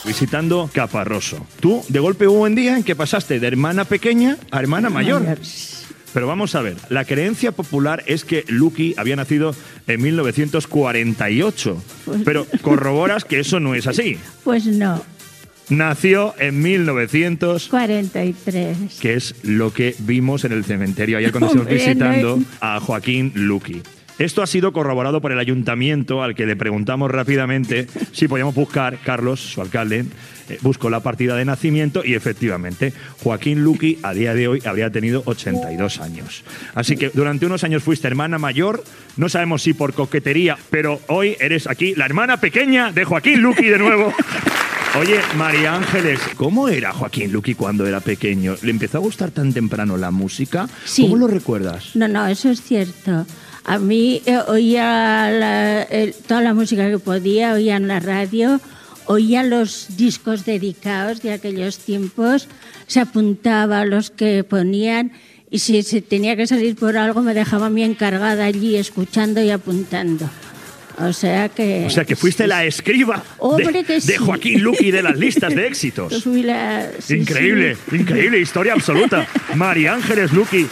Fragment d'un programa especial ,des de Caparroso, el poble de naixement de Joaquín Luqui.
Musical
FM